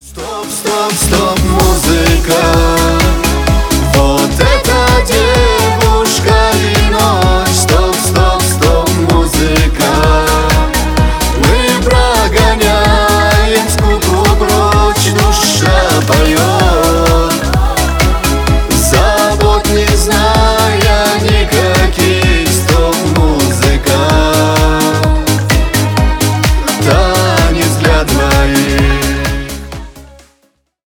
грустные
кавказские , поп